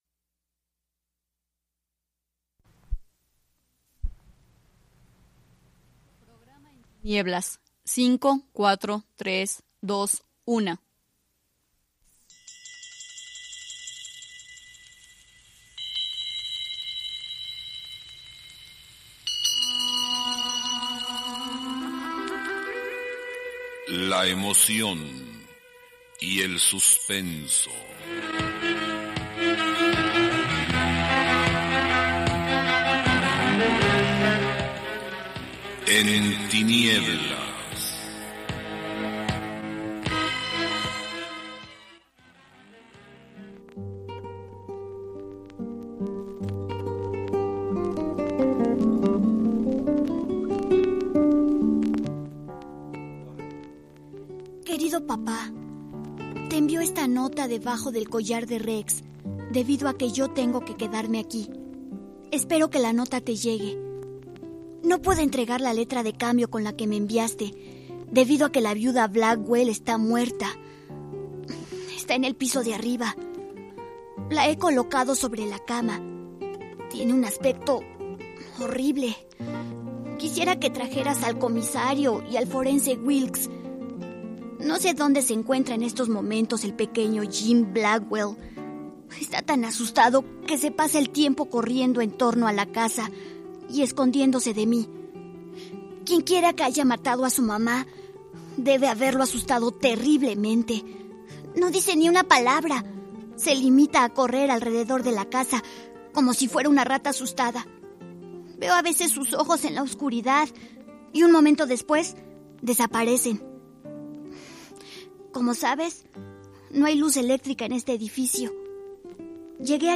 Escucha “Cambio de cementerio” de Richard Matheson en el programa “En tinieblas”, transmitido en 1988.